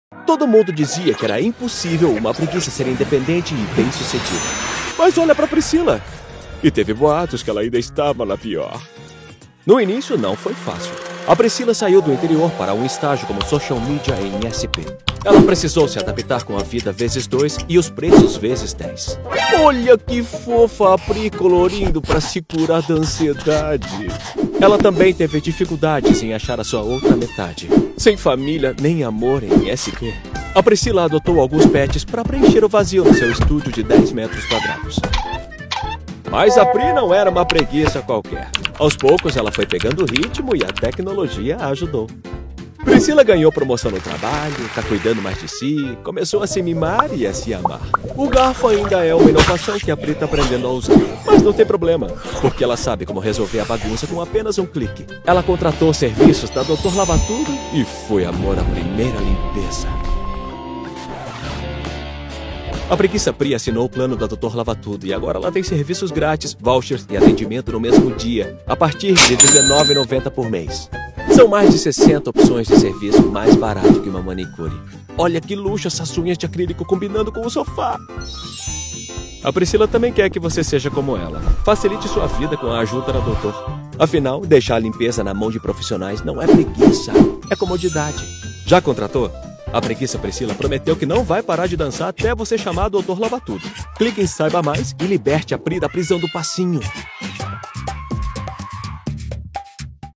Com uma gama de graves e contralto e configuração de estúdio profissional, ele oferece serviços de locução amigáveis e especializados para marcas que buscam clareza e autenticidade.
Focusrite Scarlet Solo + microfone Akg c3000
GravesContralto
DinâmicoNeutroAmigáveisConversacionalCorporativoVersátil